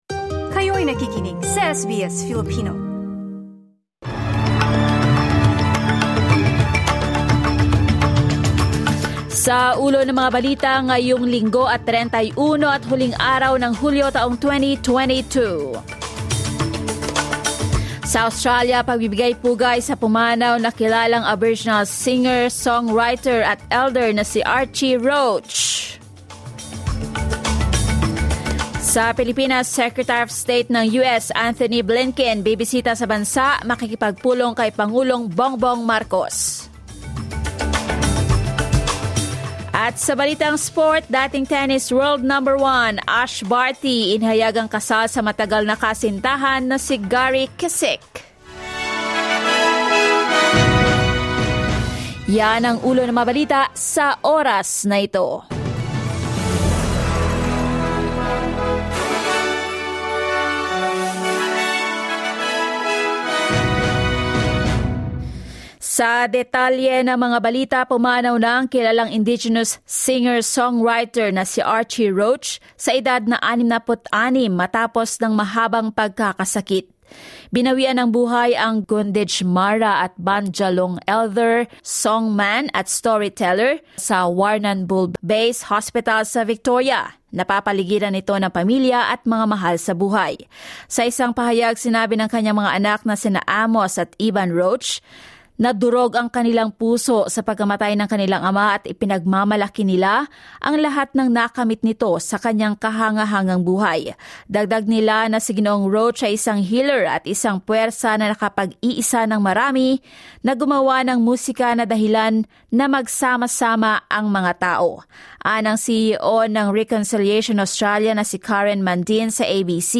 SBS News in Filipino, Sunday 31 July